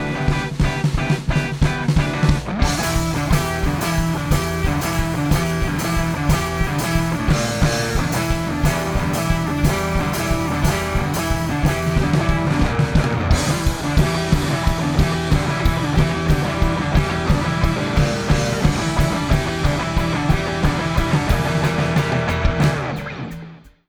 You can hear their Warmorth Tele being used, it is a thinline with a Humbucker in the neck, the first 2 bars are the clean tele tone and then Fuzzsilla kicks in... the track is VERY raw, the song unmixed and as it went down to proverbial tape (on in this case Pro Tools). It was played through my '67 Fender Bandmaster and accompanying 2x12 with Jensen C12N's.
fuzzsilla_demo_clip.wav